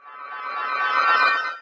stars_on.ogg